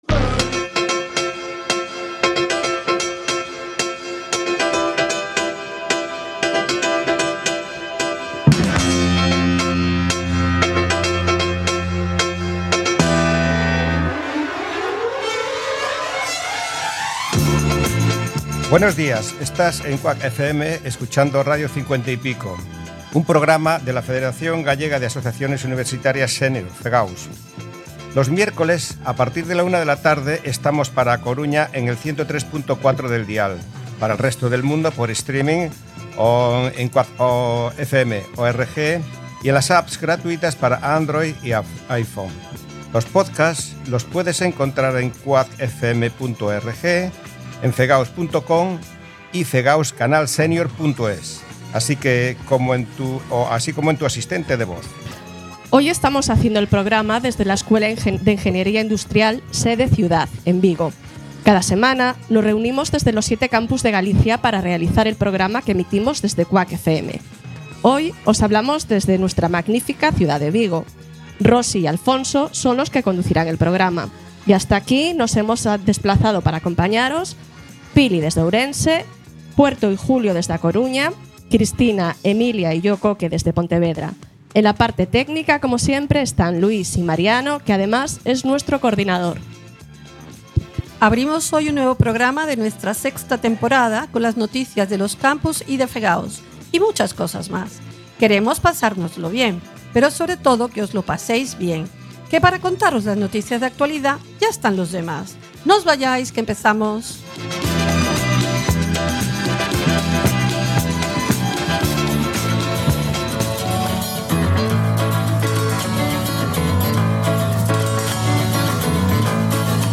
Radio 50 y Pico es un proyecto de comunicación de la Federación Gallega de Asociaciones Universitarias Senior (FEGAUS). Se realiza desde cinco de los siete campus universitarios de Galicia y aborda todo tipo de contenidos de interés, informativos, culturales, de actualidad y de entretenimiento.